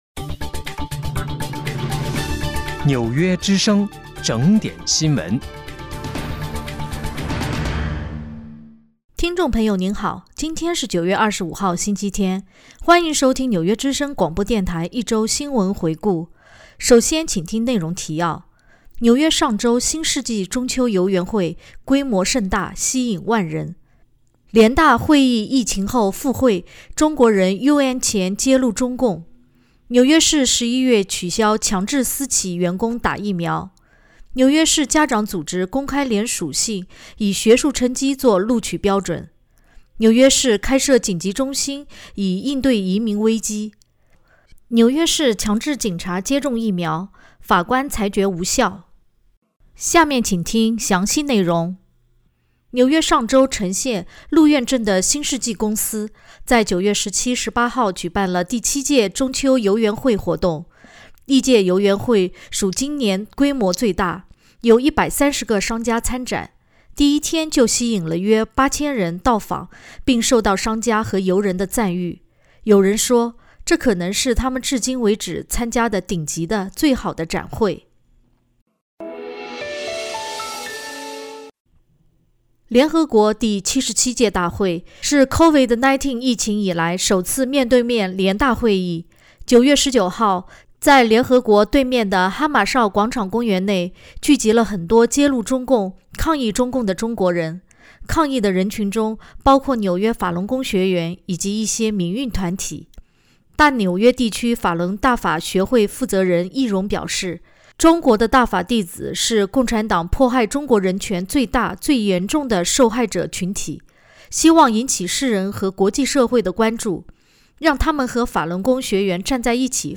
9月25号（星期天）纽约一周新闻回顾。